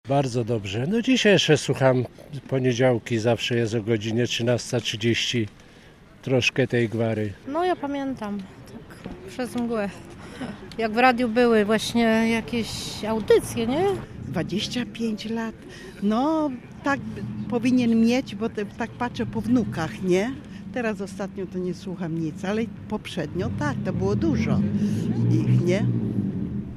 Poznaniacy przechodzący dziś obok pomnika dobrze wspominają gwarowe audycje.